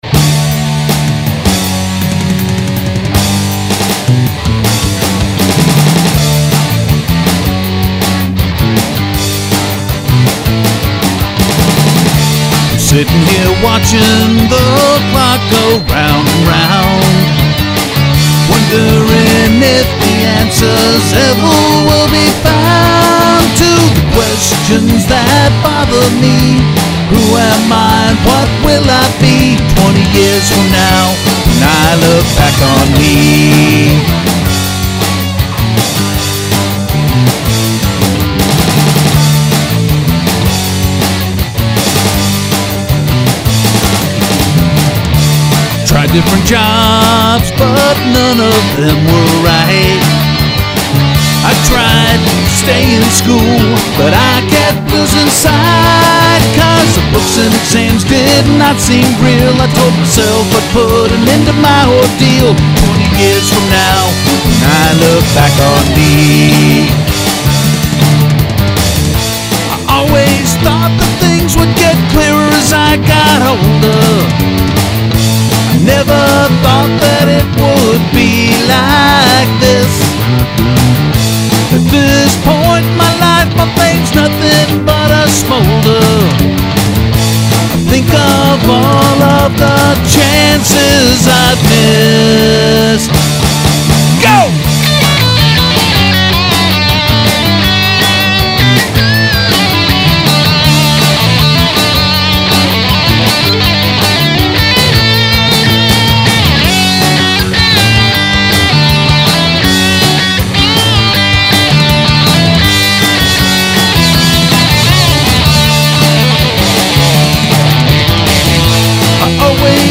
Conservative rock, Boise